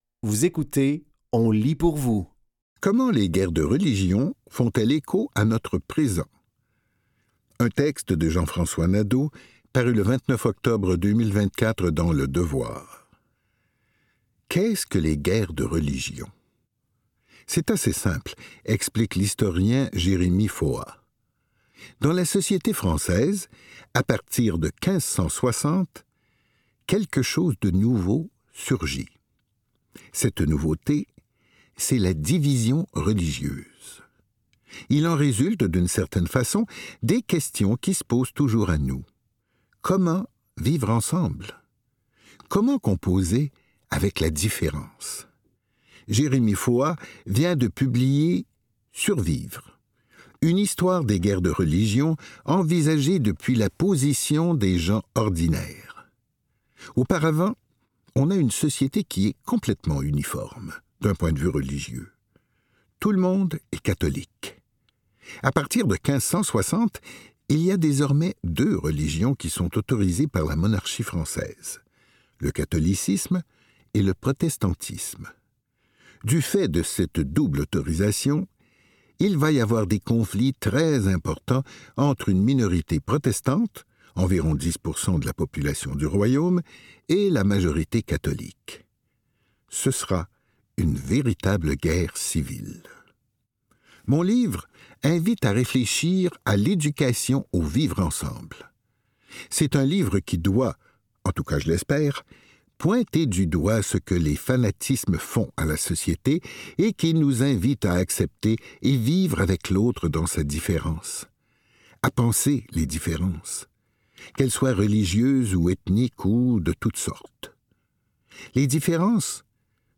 Dans cet épisode de On lit pour vous, nous vous offrons une sélection de textes tirés des médias suivants : Le Devoir, La Presse et Le Moniteur acadien.